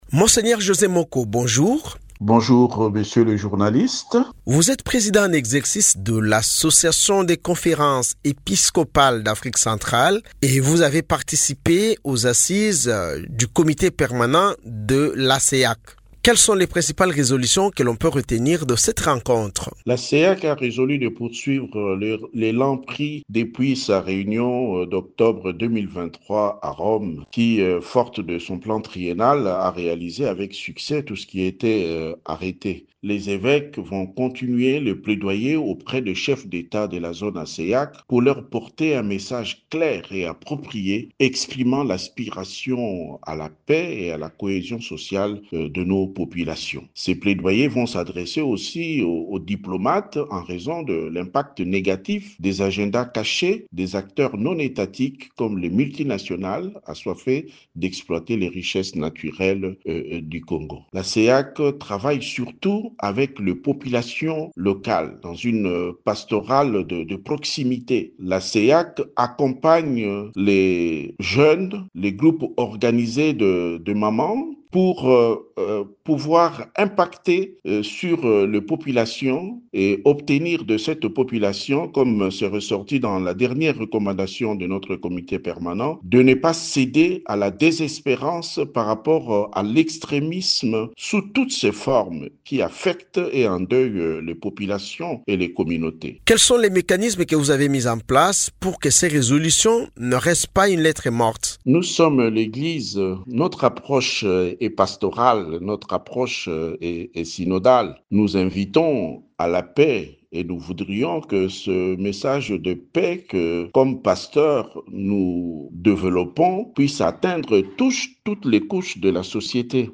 Mgr José Moko, évêque d’Idiofa et président en exercice de l’Association des conférences épiscopales d’Afrique centrale (ACEAC), est revenu, ce mardi 12 aout dans une interview à Radio Okapi, sur les principales résolutions adoptées à l’issue des assises du comité permanent de l’organisation.